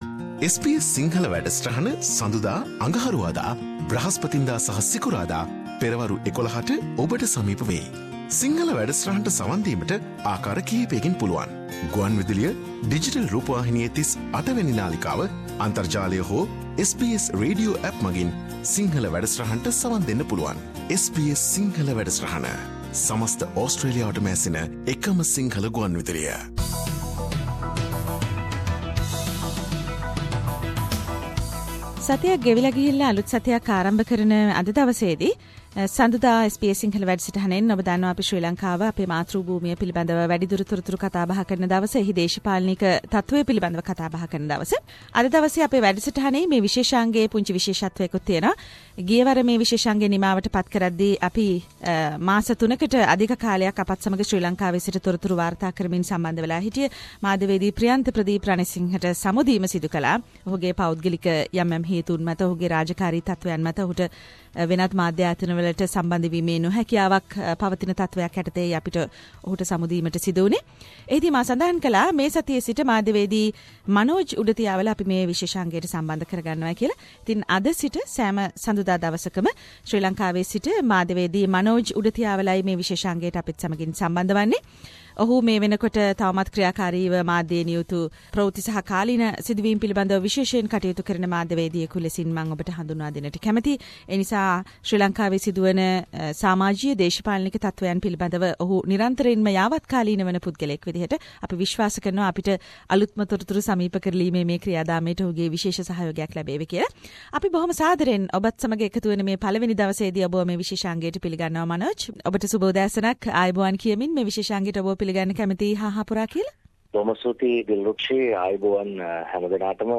SBS Sinhala